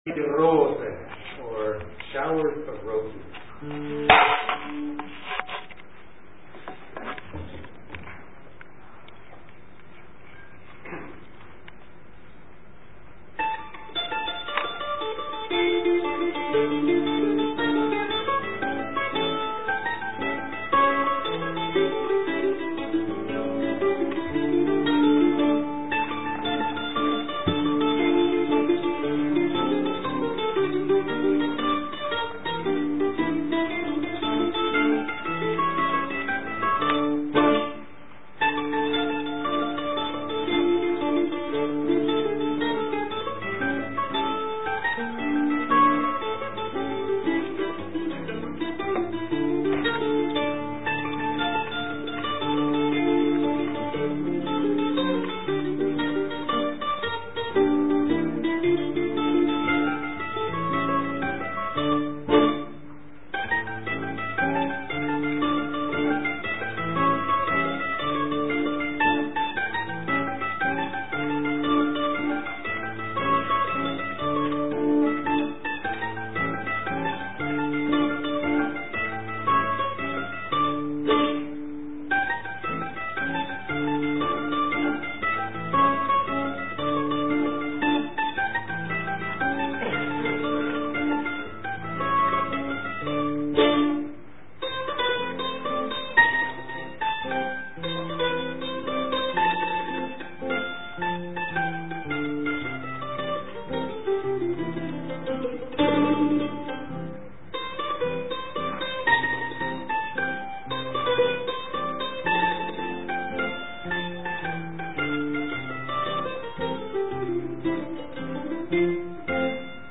Mandolin solo
above - a large audience came to enjoy the evening of music and comedy